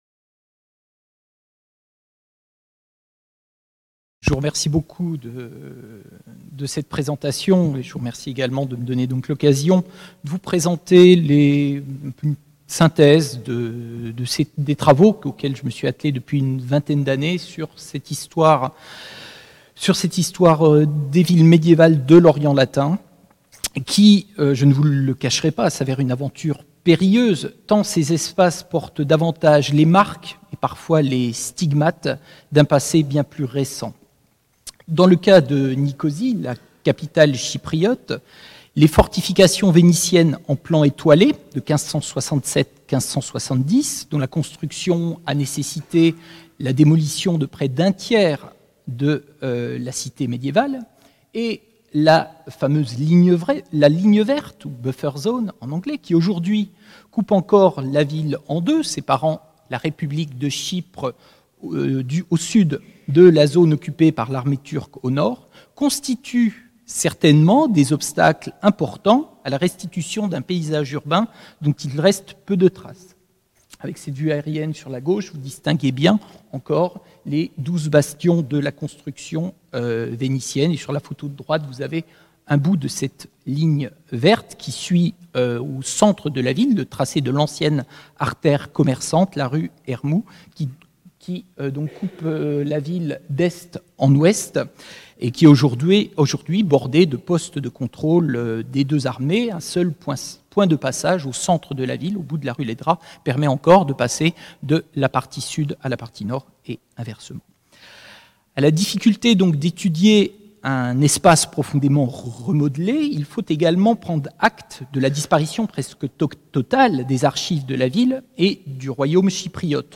Cette conférence se propose d’examiner le cas de Nicosie, capitale du royaume de Chypre sous la domination de la dynastie franque des Lusignan de 1192 à 1474. Il s’agit de questionner l’inscription du pouvoir dans l’espace urbain, aussi bien du point de vue de l’urbanisme que de l’organisation politique et sociale de la ville.